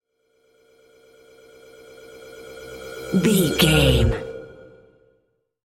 Sound Effects
funny
magical
mystical